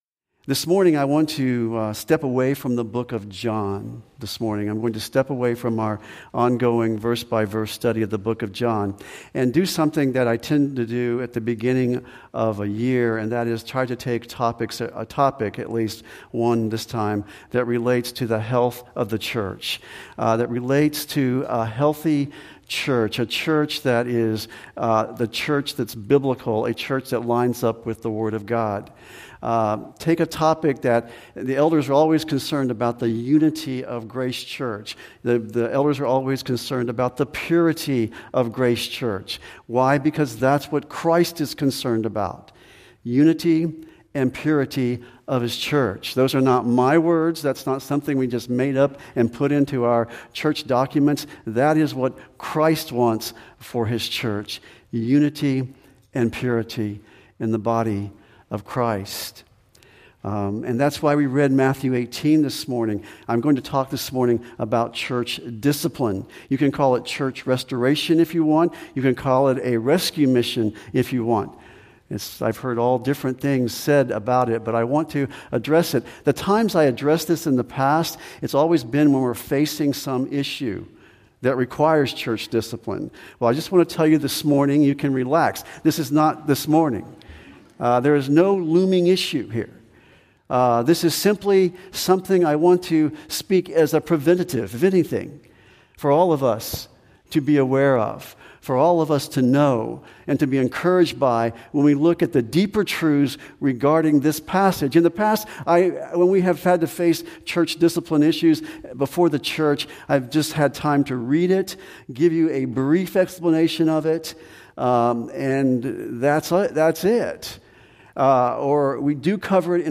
Sermons preached at Grace Church of Tallahassee, where the Word of God has supreme authority in all matters of faith and conduct and is sufficient for all matters of life and godliness.